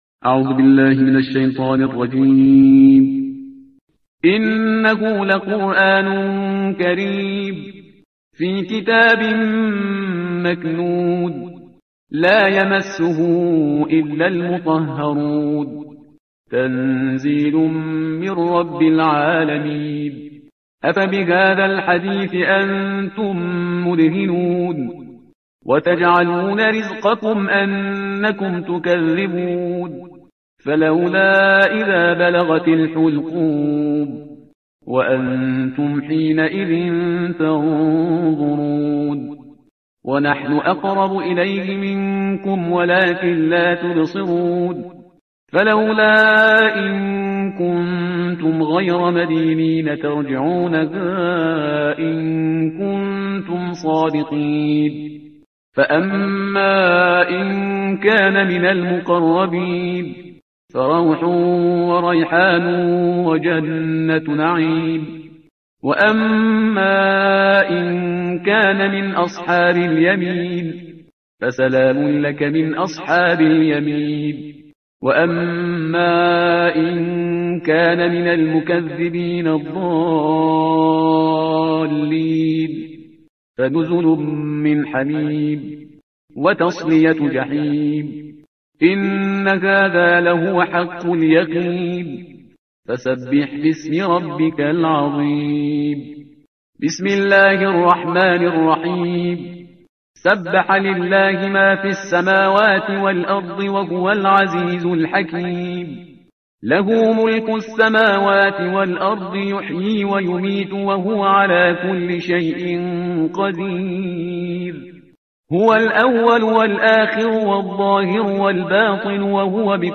ترتیل صفحه 537 قرآن – جزء بیست و هفتم